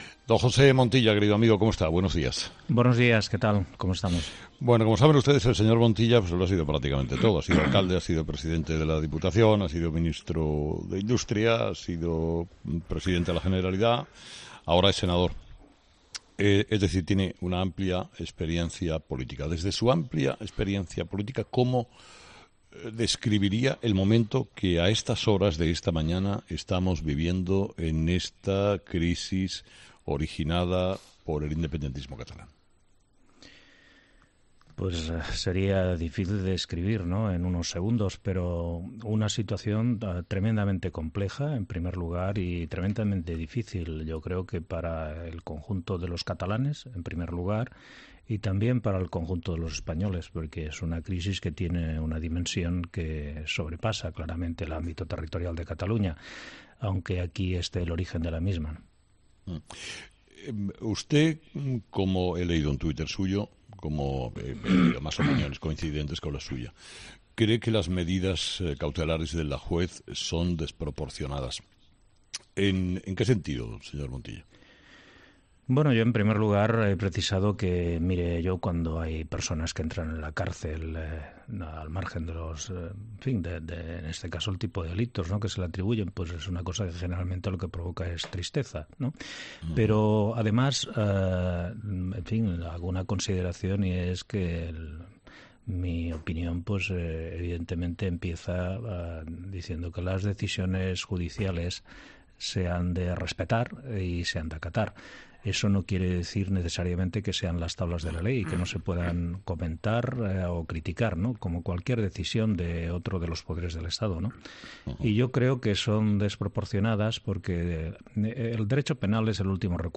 Escucha al expresidente de la Generalitat y exlíder del PSC José Montilla